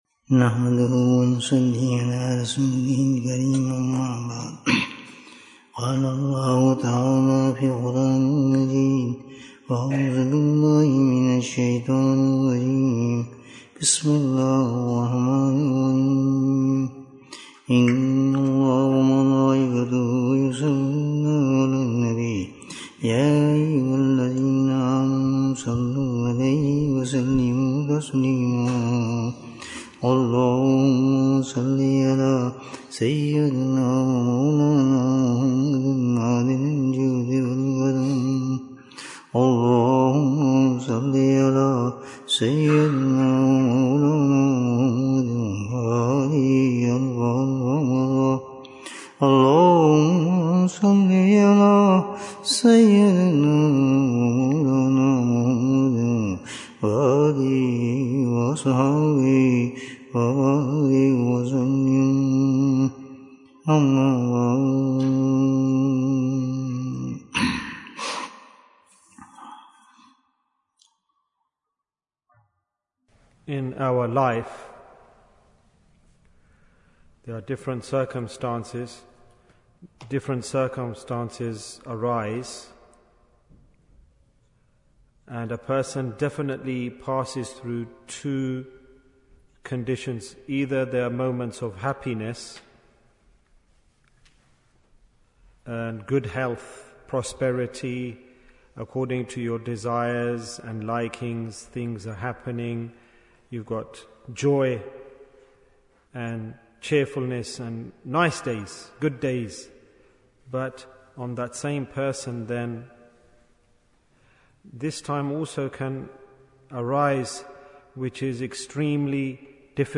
For Whom do Angels Make Dua? Bayan, 31 minutes8th June, 2023